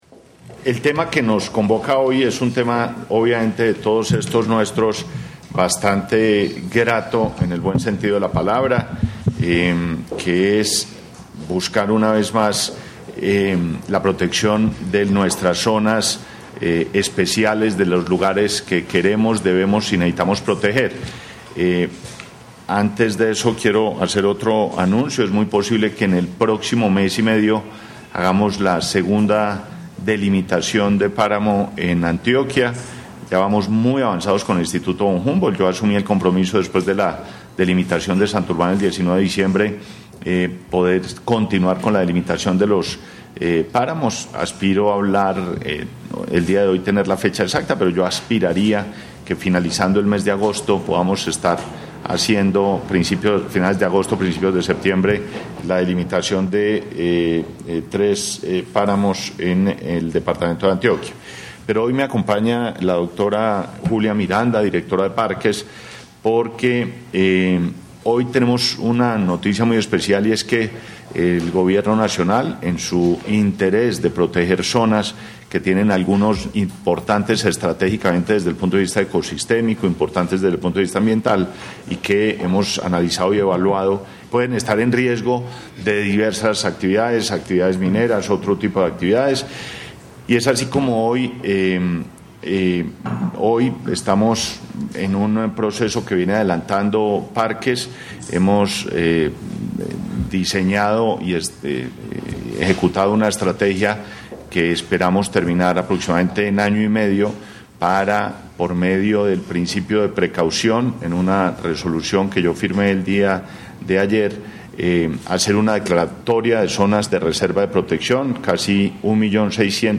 El Ministro de Ambiente y Desarrollo Sostenible, Gabriel Vallejo López, explicó que a partir de hoy, con la expedición de la resolución 1628 de 2015, la Agencia Minera deberá inscribir estos polígonos en el Catastro Minero Nacional y
16-VOZ_M.VALLEJO.mp3